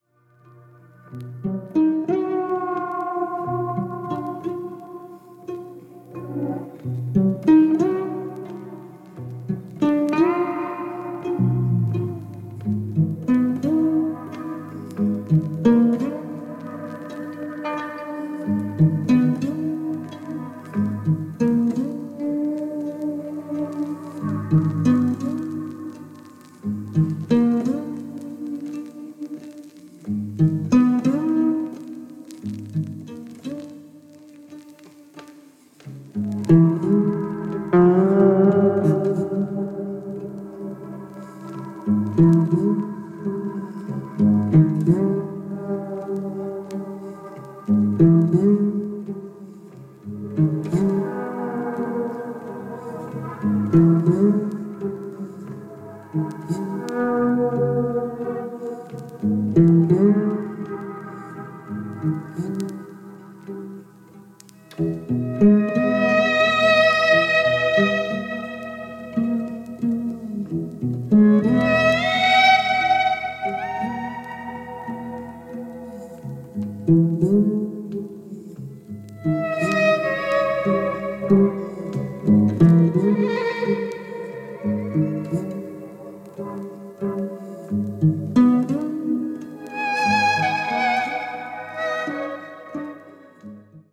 Cello
Electric Guitar, Keyboards